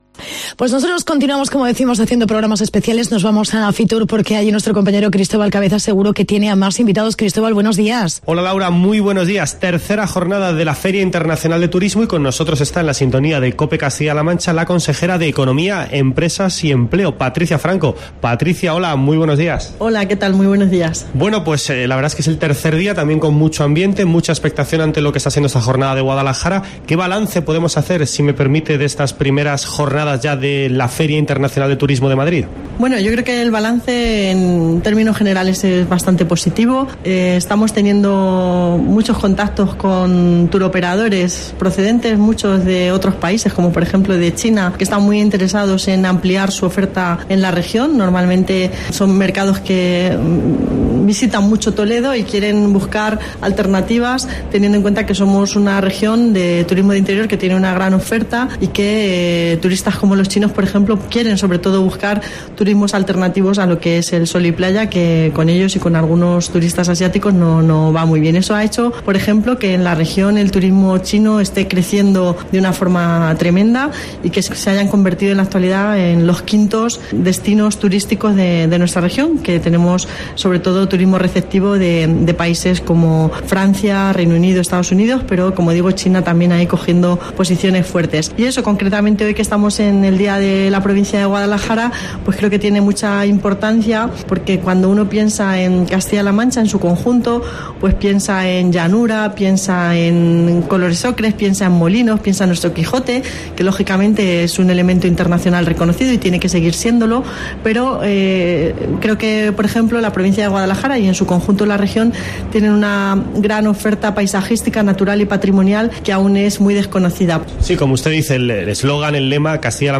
Entrevista con Patricia Franco, consejera de Economía
AUDIO: Programación especial de COPE Castilla-La Mancha con motivo de FITUR 2018.